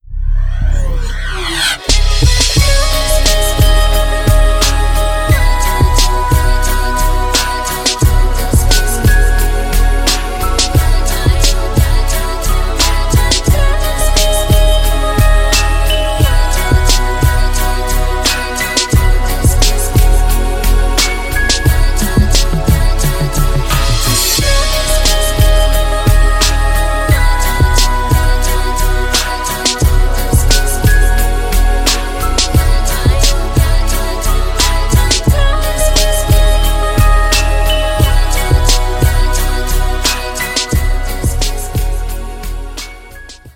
• Качество: 320, Stereo
dance
club
mix